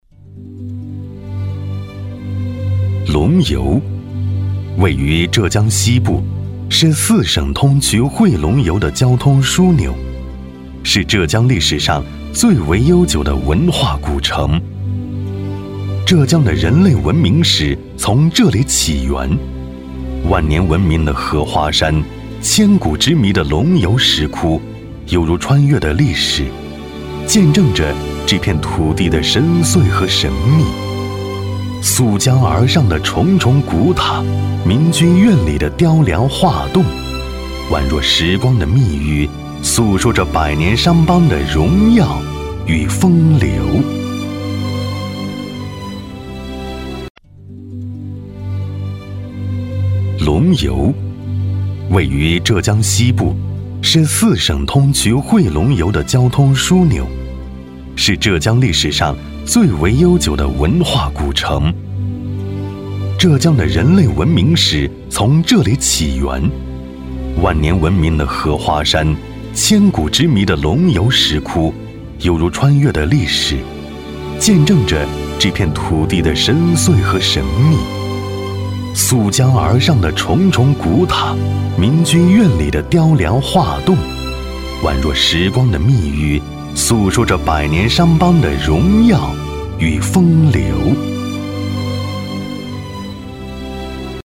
男S356 国语 男声 宣传片浙江龙游石窟 景点宣传片 大气浑厚 大气浑厚磁性|沉稳